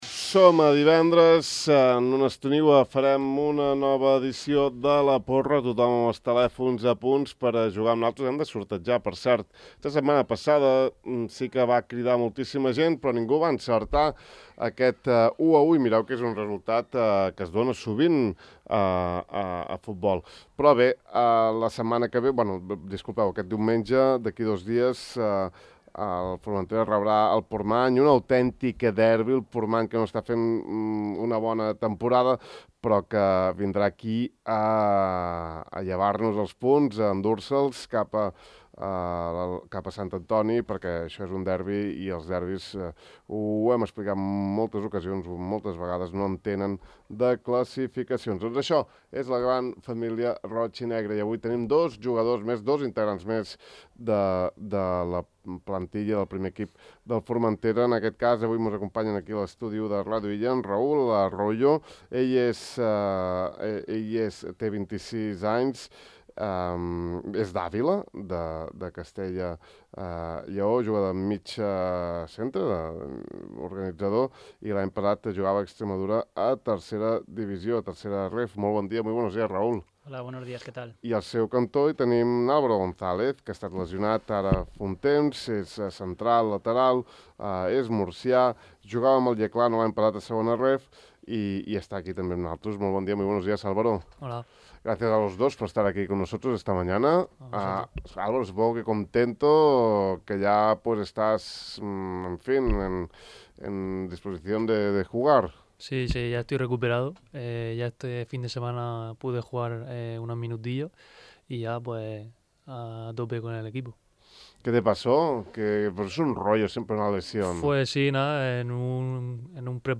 Podeu escoltar l’entrevista sencera als dos jugadors arribats aquesta temporada a la SD Formentera, clicant el reproductor: